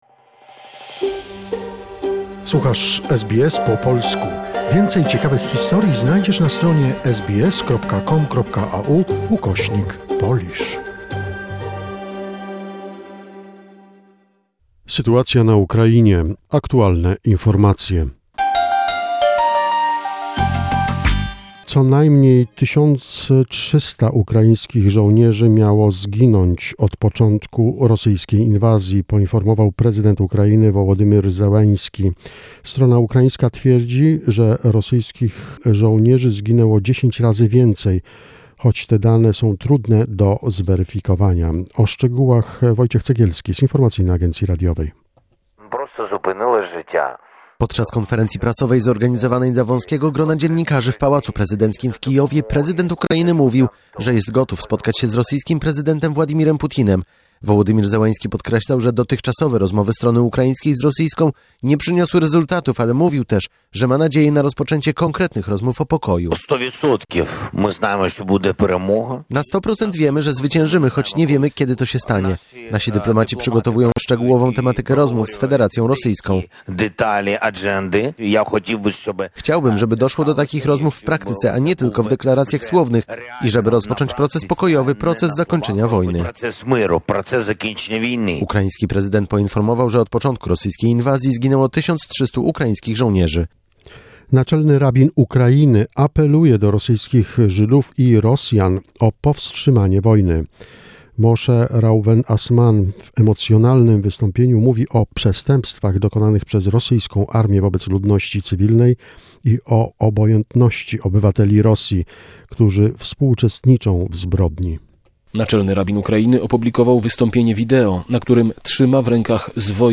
The most recent information and events about the situation in Ukraine, a short report prepared by SBS Polish.